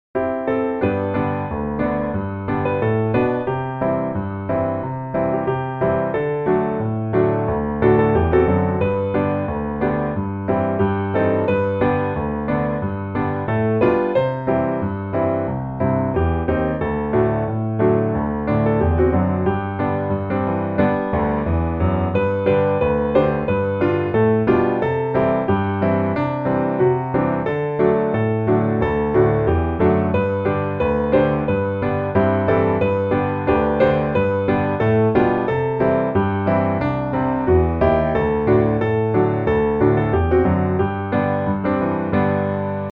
G Dur